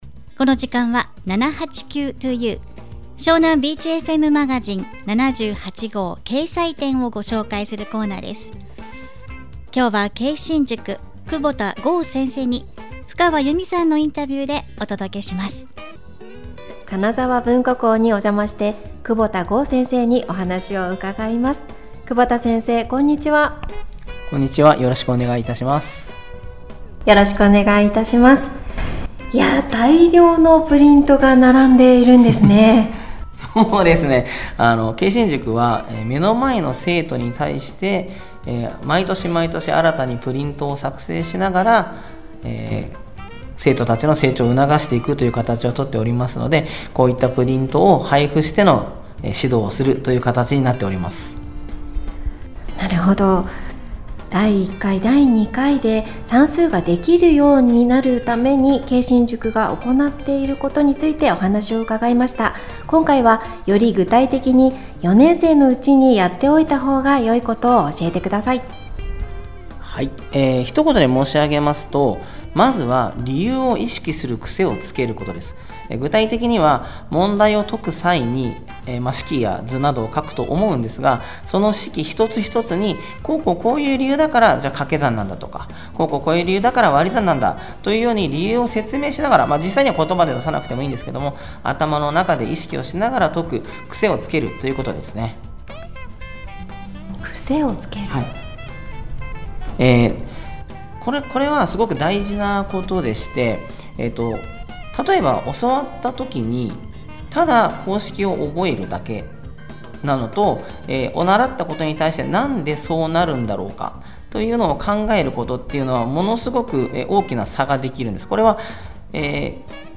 ラジオ番組に出演いたしました！